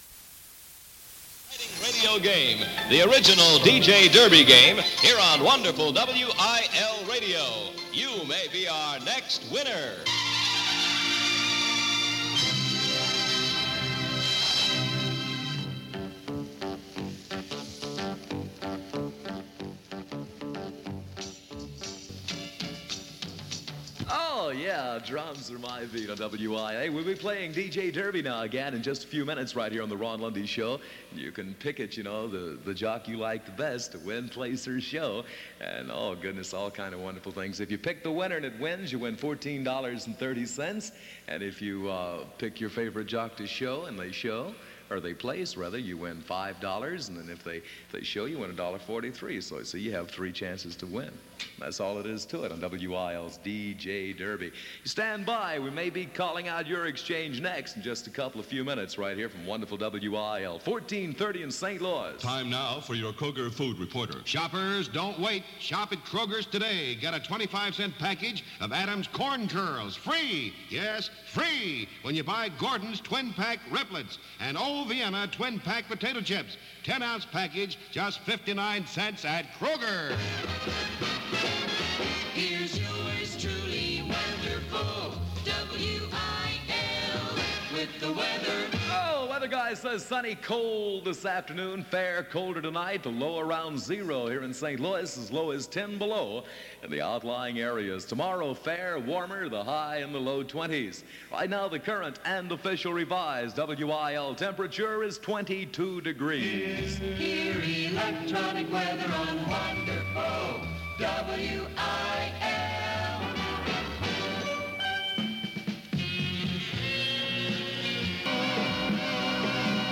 WIL-AM Lundy, Ron aircheck · St. Louis Media History Archive
Original Format aircheck